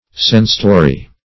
sensitory - definition of sensitory - synonyms, pronunciation, spelling from Free Dictionary Search Result for " sensitory" : The Collaborative International Dictionary of English v.0.48: Sensitory \Sen"si*to*ry\, n. See Sensory .